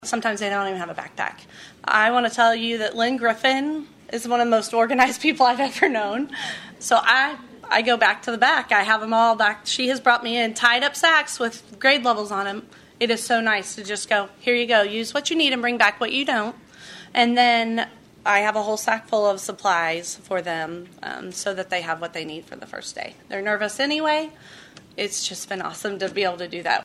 Bright Futures Trenton Advisory Board members and Trenton R-9 school counselors spoke at the Bright Futures Trenton Volunteer Recognition and Celebration Luncheon on October 27.